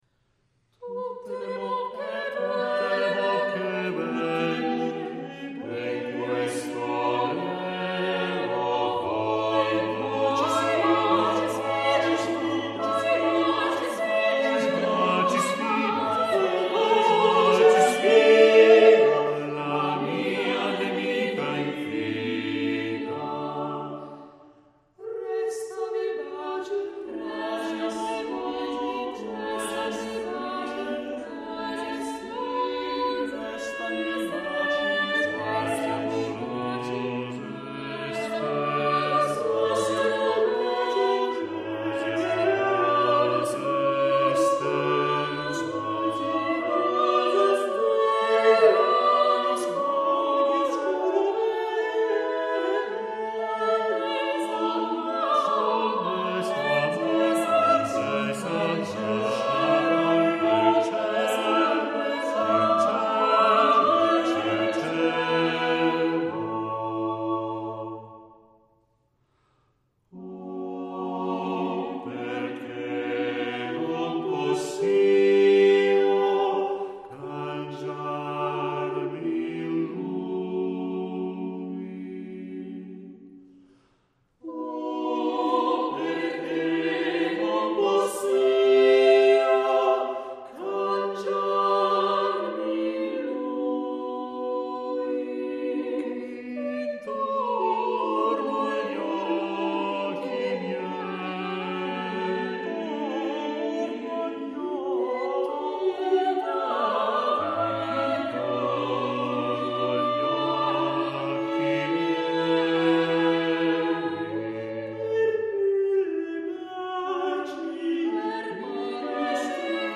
Győr Girls' Choir (Hungaroton 1979)